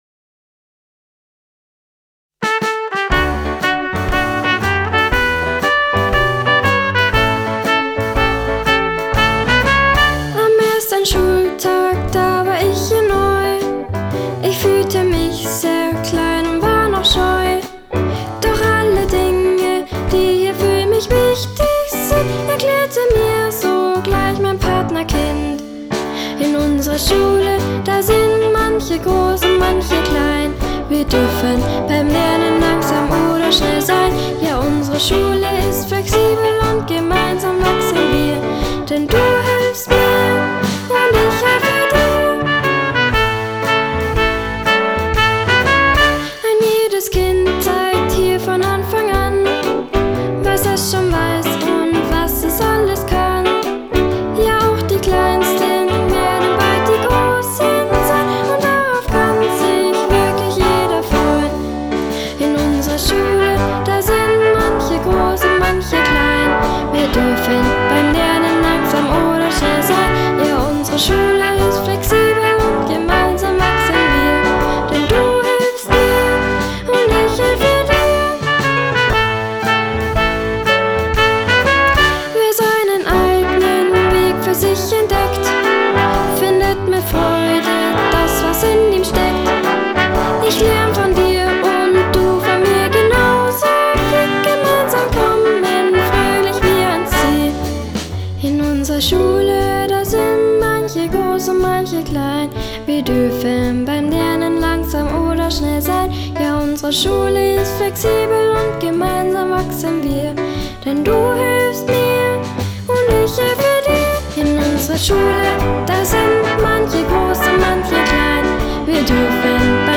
• Lied: Unsere Schule (mit Gesang)
Unsre_Schule_Mix_v3.wav